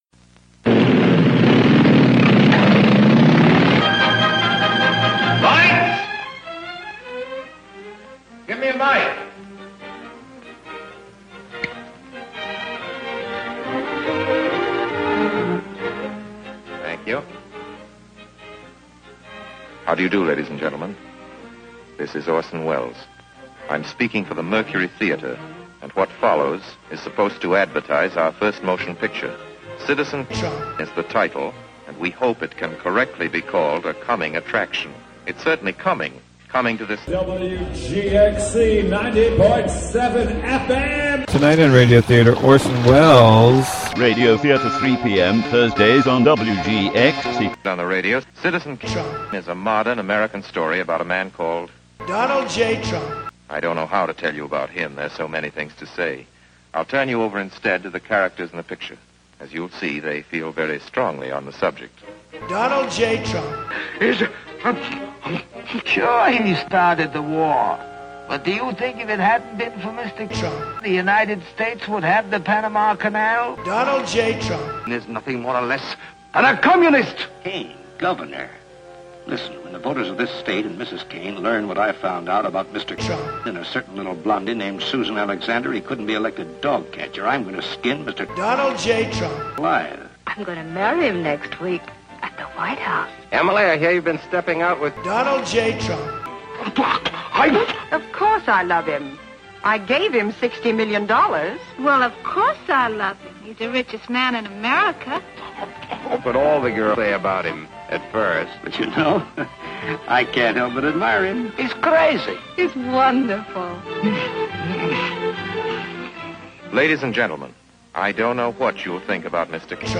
This is the original "Citizen Kane" movie trailer, with "Donald J. Trump" cut in whenever it says "Charles Foster Kane."